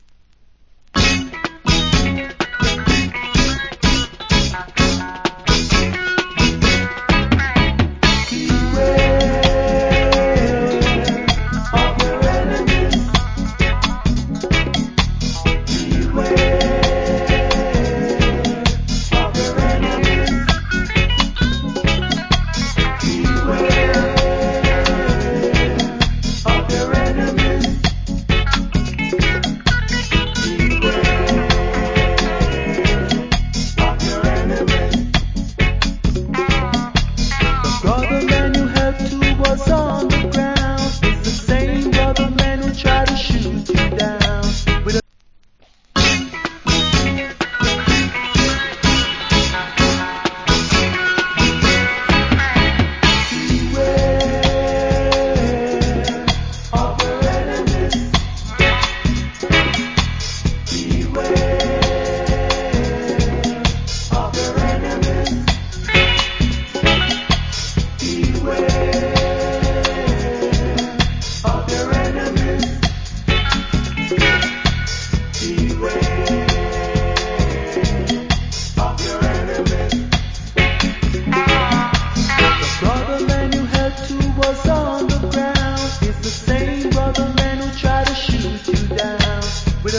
Wicked Roots Rock Vocal.